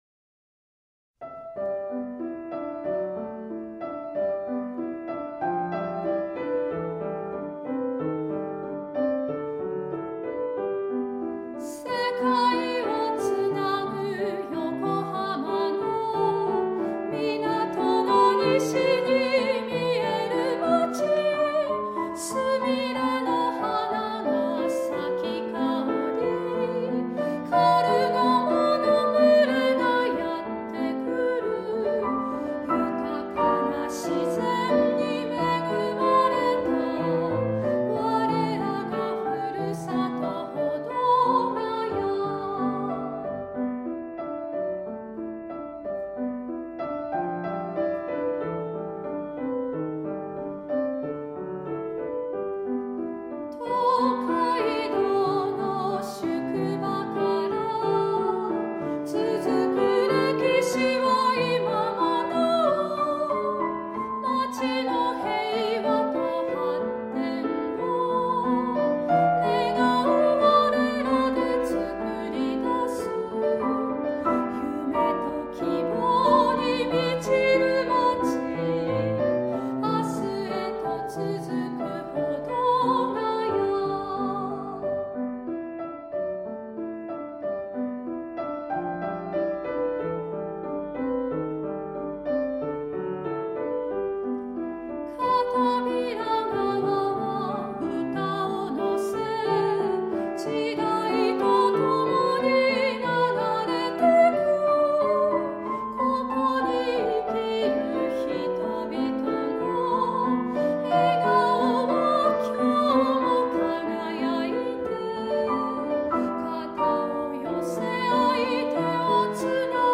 Soprano Solo